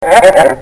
cartoon31.mp3